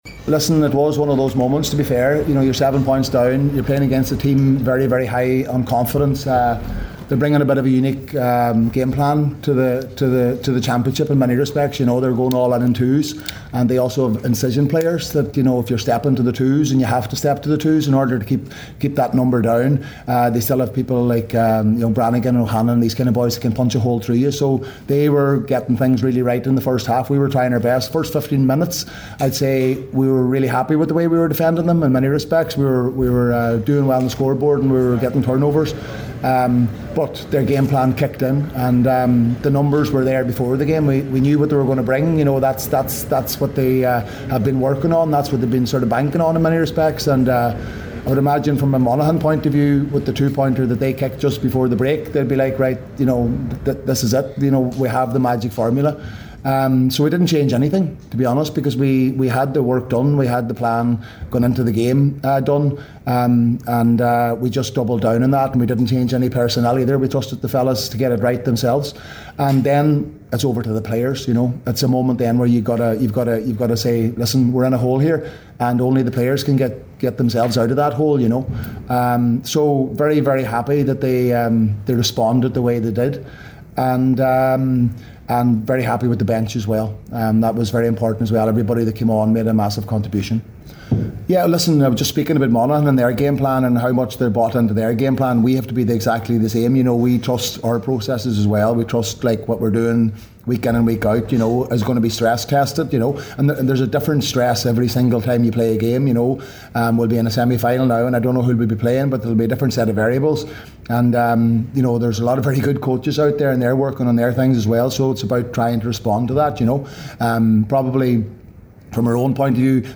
Donegal manager Jim McGuinness
After the game, Jim McGuinness was asked what was said at half time…